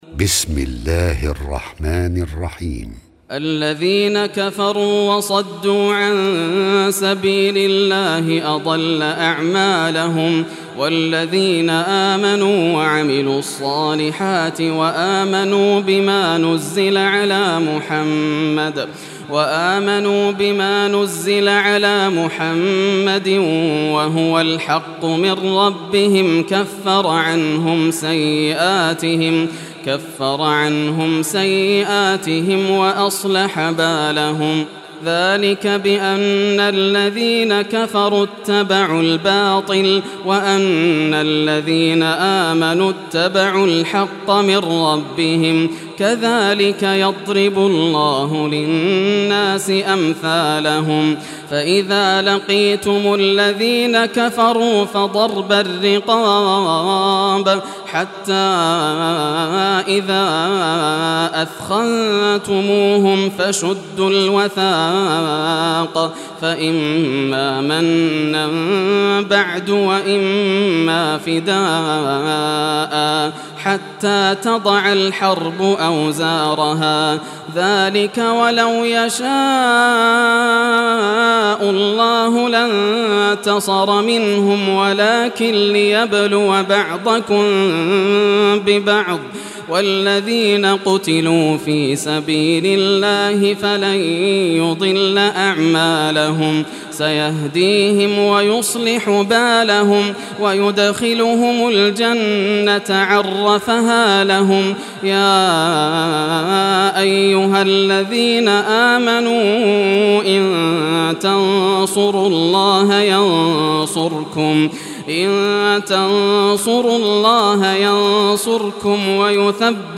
Surah Muhammad Recitation by Yasser al Dosari
Surah Muhammad, listen or play online mp3 tilawat / recitation in arabic in the beautiful voice of Sheikh Yasser al Dosari.